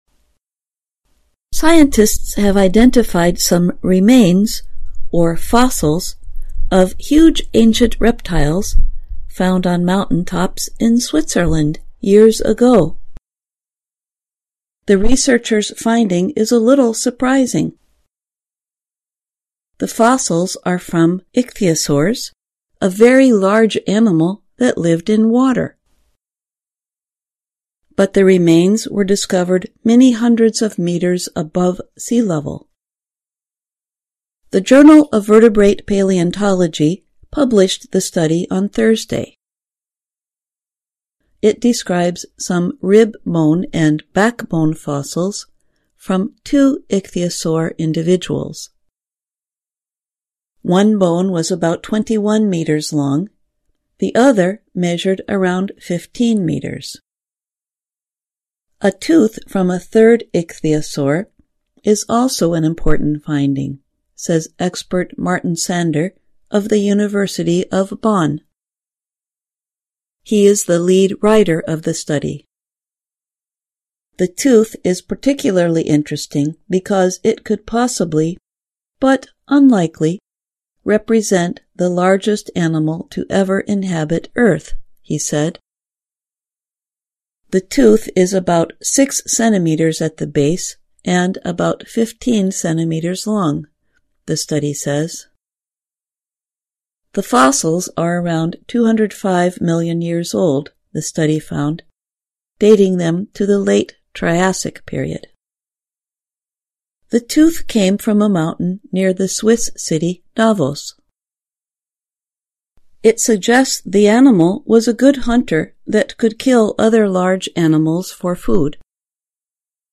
VOA慢速英语逐行复读精听提高英语听力水平